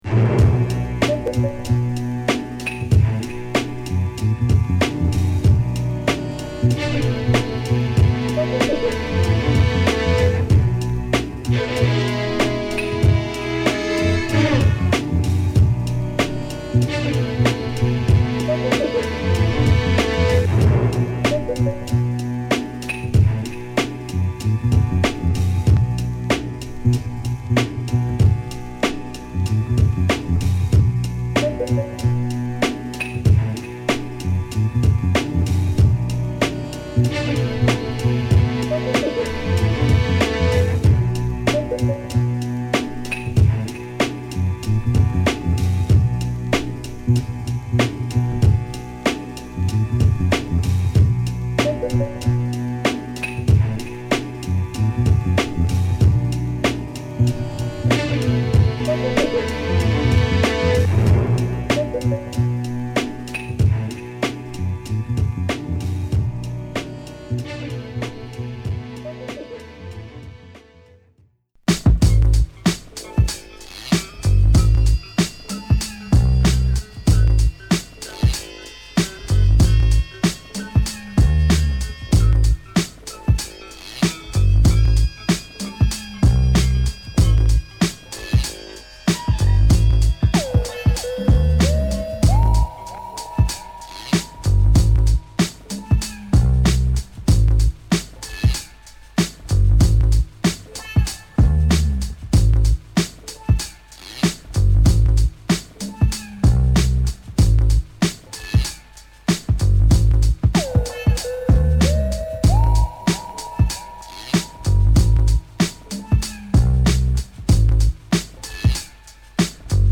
タイトなサンプリングビーツに、ジャズファンクやサントラをネタに絡めた誇りっぽく男臭いインストトラックを13曲収録！！